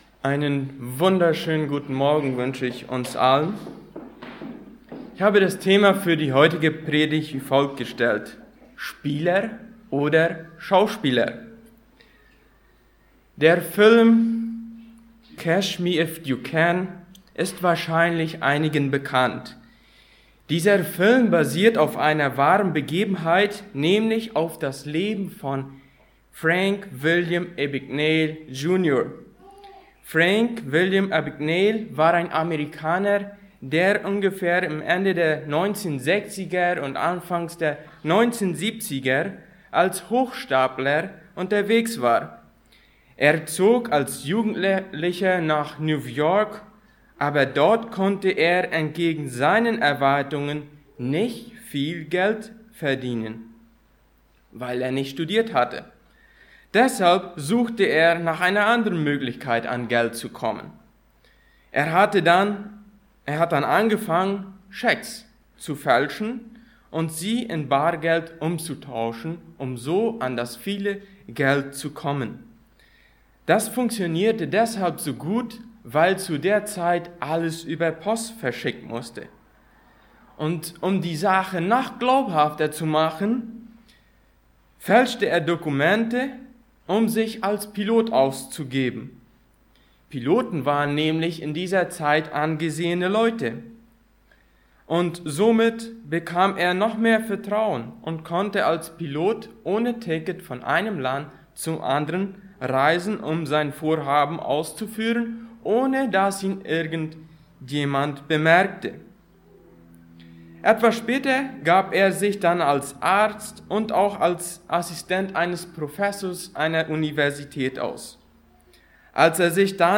Bible Text: 1. Johannes 2,3-6 | Prediger/in